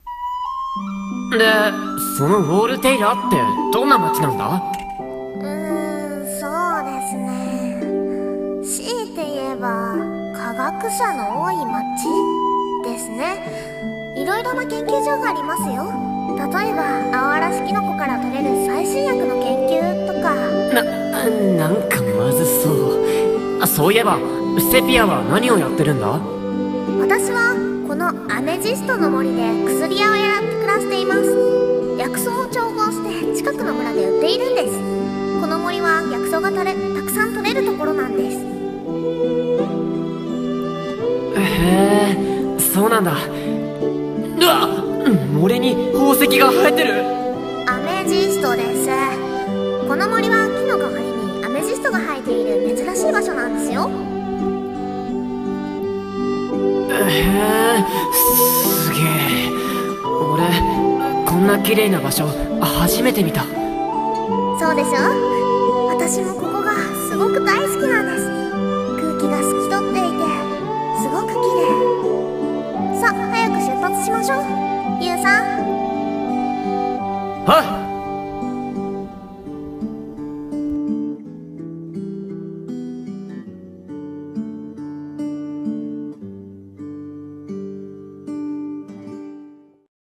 声劇台本｢異世界への漂流者３｣